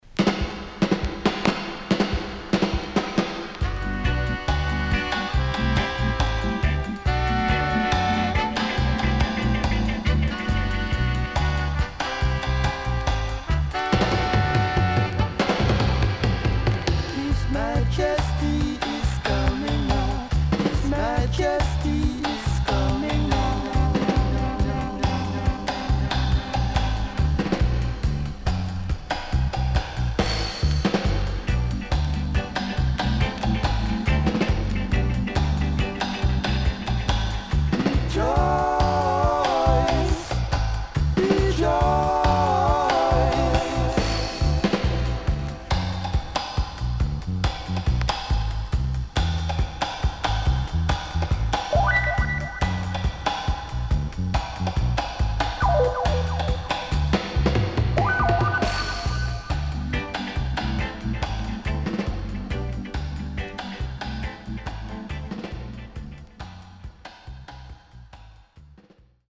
HOME > REGGAE / ROOTS  >  STEPPER
Great Stepper Roots Vocal & Dubwise
SIDE A:少しプチノイズ入ります。